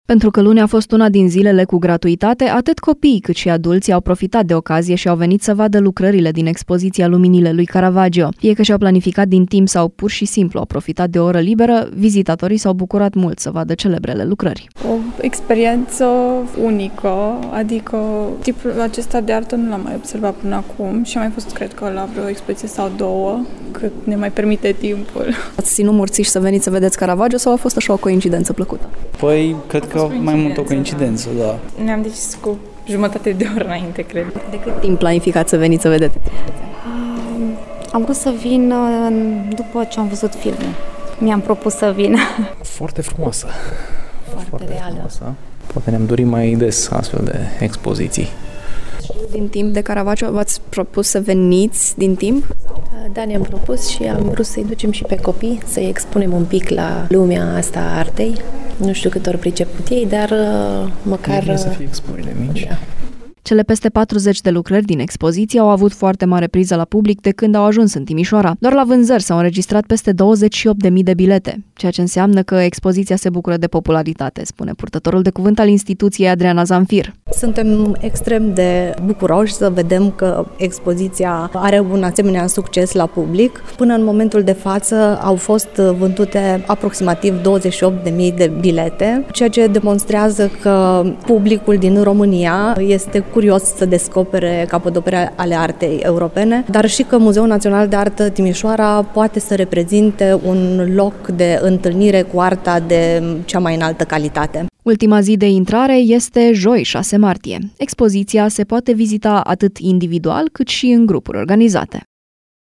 O experiență unică, adică acest gen de artă nu l-am mai observat până acum și a mai fost, cred că, la vreo expoziție sau două, cât ne mai permite timpul”, spune o vizitatoare.
Poate ne-am dori mai des astfel de expoziții”, spune un vizitator.